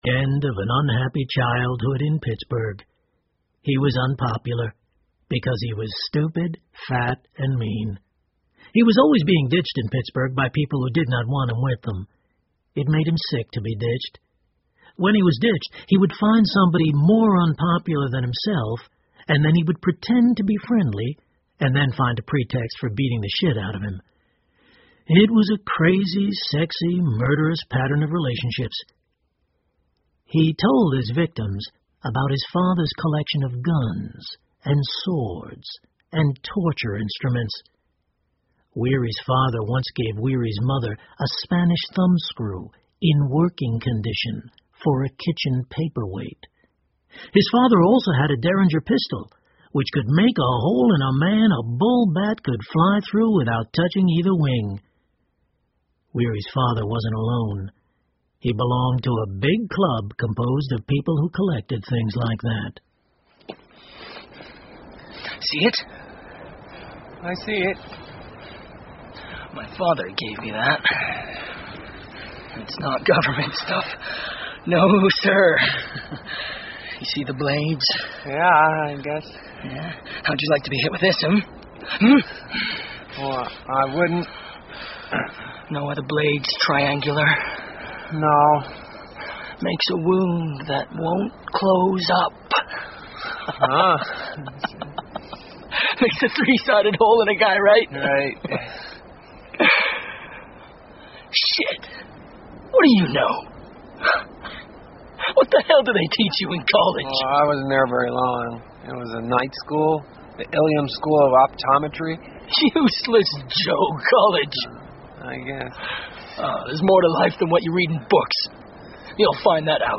英文广播剧在线听 Slaughterhouse Five 五号屠场 04 听力文件下载—在线英语听力室